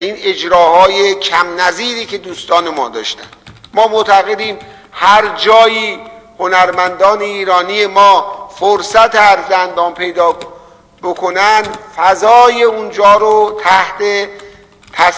محمدمهدی اسماعیلی، وزیر فرهنگ و ارشاد اسلامی در مراسم اختتامیه چهاردهمین جشنواره هنرهای تجسمی فجر ضمن تبریک حلول مبارک ماه شعبان بیان کرد: به سهم خود از همه اساتید، هنرمندان و دست‌اندرکاران عزیز که در این جشنواره حضور داشتند تشکر می‌کنم.